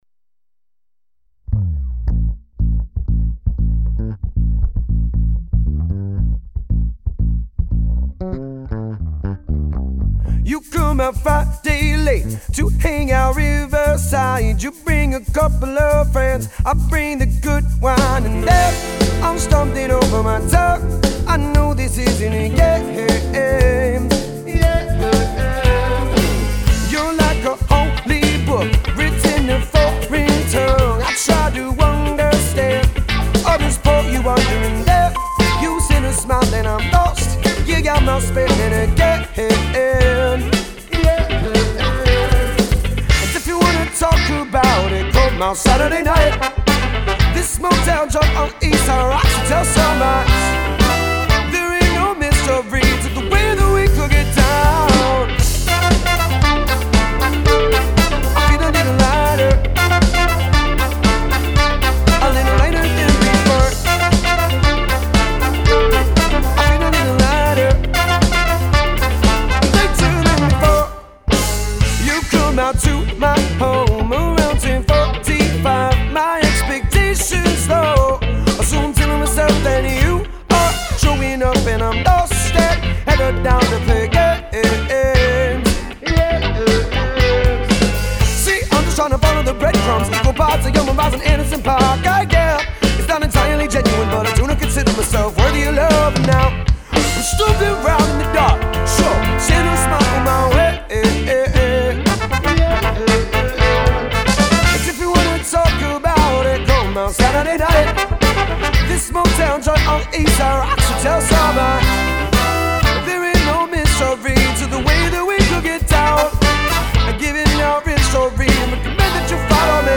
Diesmal wohl keine Livespuren: